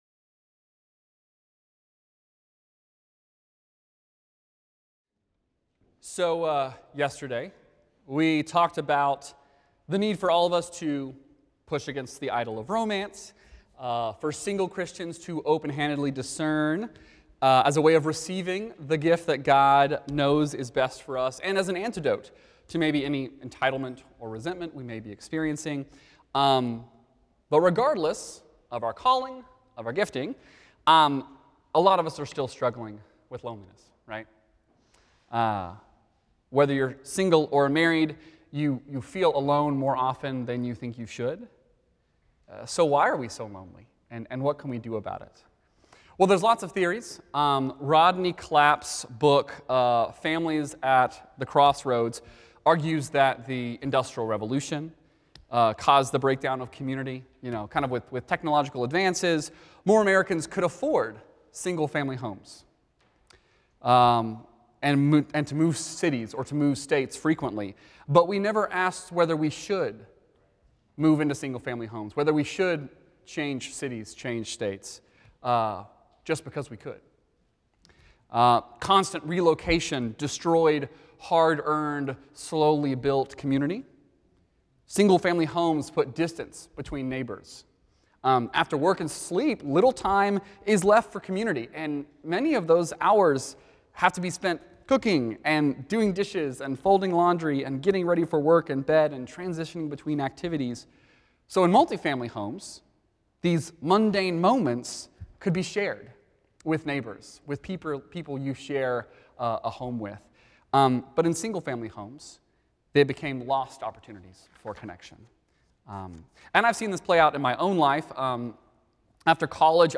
Message Archives - Asbury Theological Seminary - Asbury Theological Seminary
The following service took place on Wednesday, February 26, 2025.